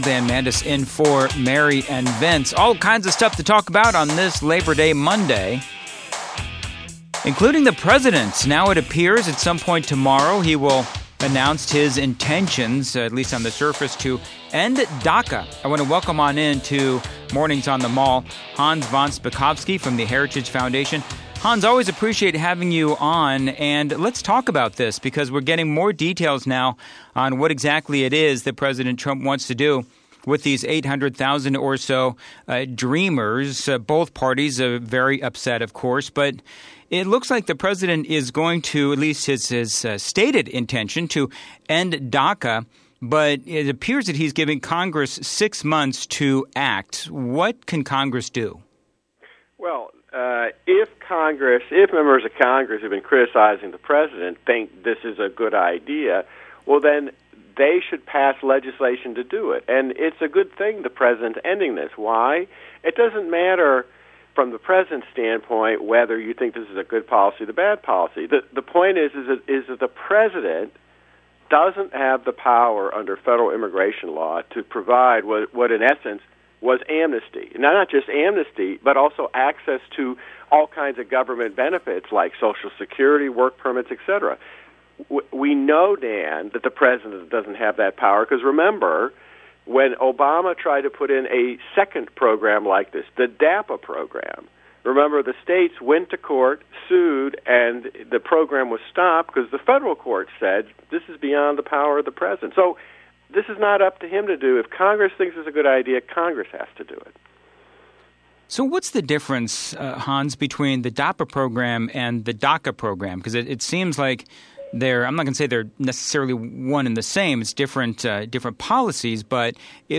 WMAL Interview - HANS VON SPAKOVSKY - 09.04.17